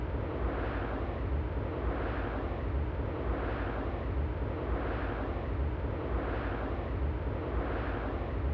Propagation effects in the synthesis of wind turbine aerodynamic noise | Acta Acustica
Test cases C: xR = 500 m, medium turbulence and grass ground in summer.